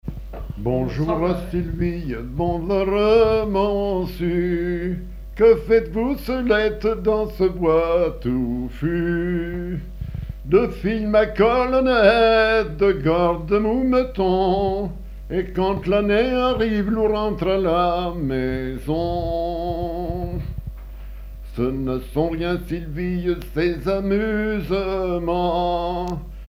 Dialogue français-arpitan
Pièce musicale inédite